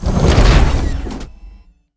droidic sounds